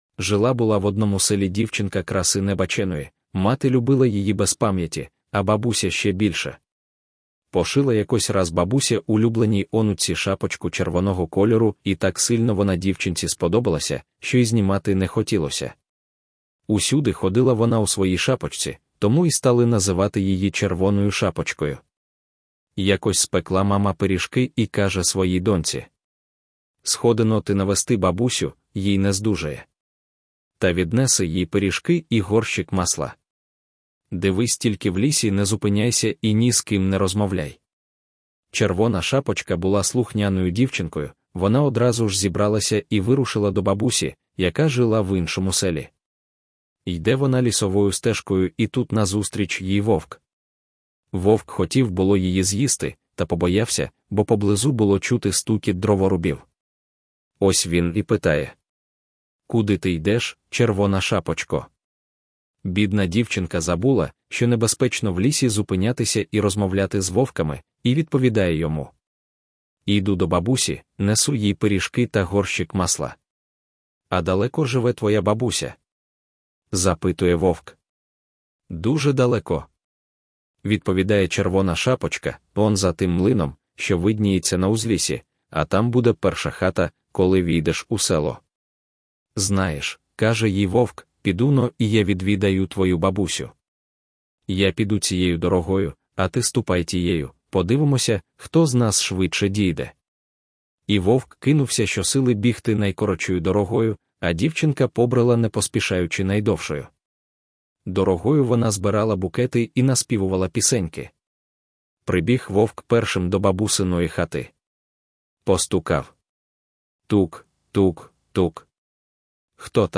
Аудіоказка Червона Шапочка